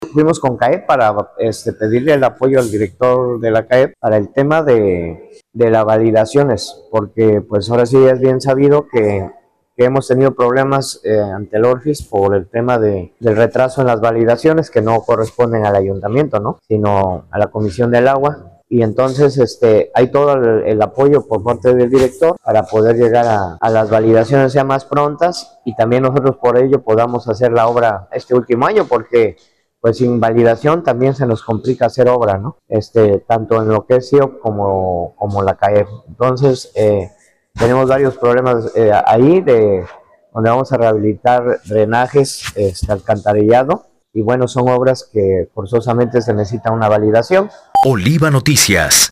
*El alcalde de Tequila, Jesús Valencia, dijo que en este año se tienen que realizar obras y no pueden ejecutar sin la validación, "también se nos complica hacer obra"
Entrevistado en Orizaba, el edil dijo que hace unos días acudió a Xalapa a la Comisión del Agua del Estado de Veracruz (CAEV), a presentar un proyecto para la rehabilitación de drenajes y alcantarillado.